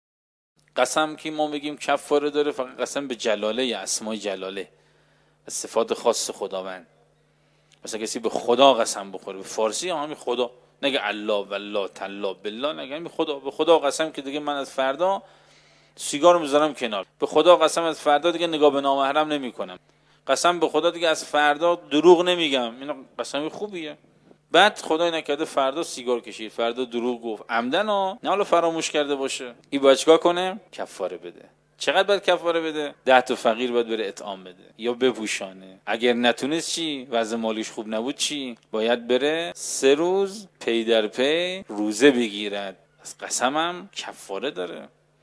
کارشناس پاسخگویی